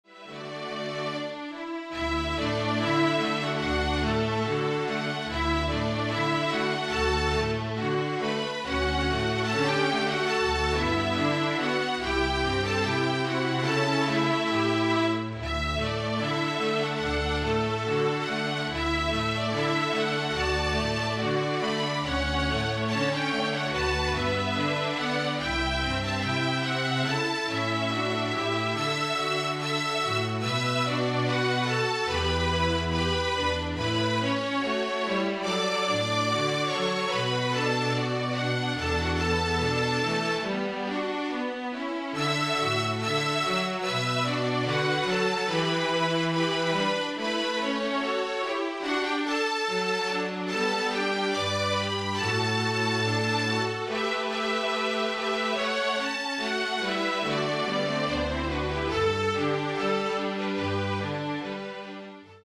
FLUTE QUARTET
(Flute, Violin, Viola and Cello)
(Two Violins, Viola and Cello)
MIDI